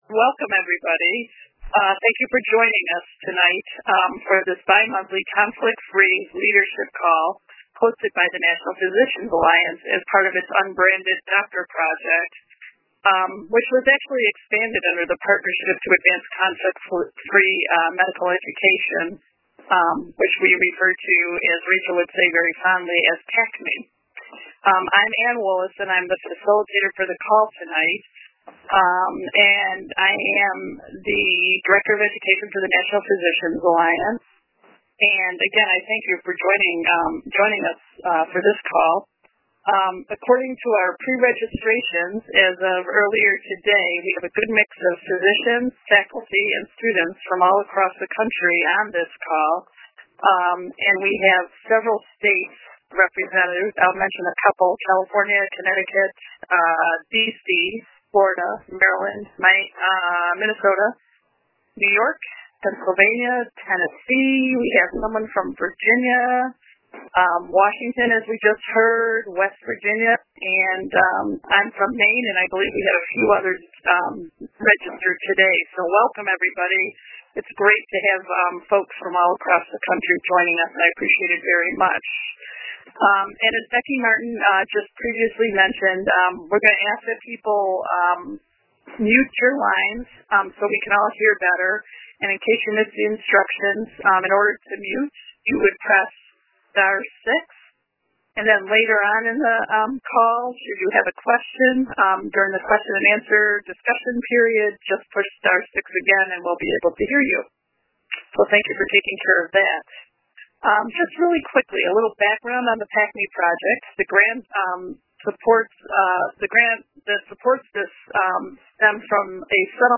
This lecture series highlights advocacy and policy experts who provide technical assistance and leadership development for physicians, residents, and medical students interested in conflict-of-interest reform efforts. This lecture was recorded on June 17, 2014.